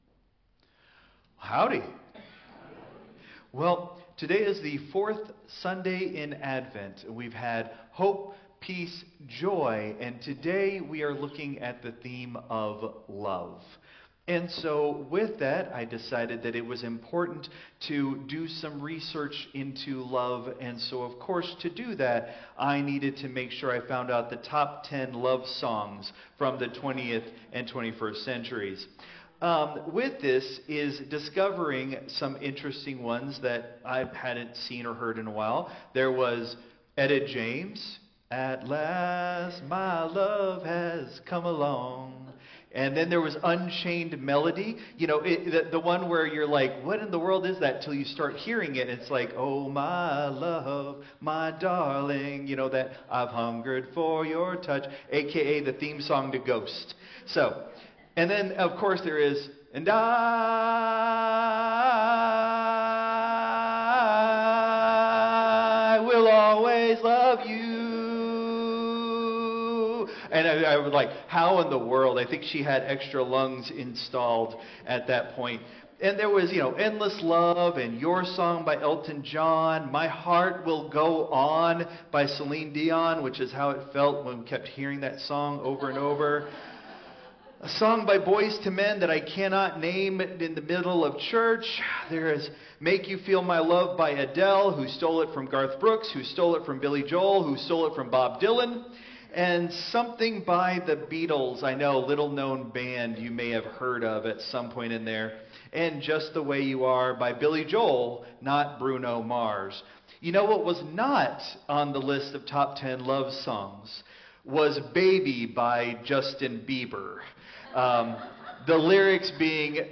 Christ Memorial Lutheran Church - Houston TX - CMLC 2024-12-22 Sermon (Traditional)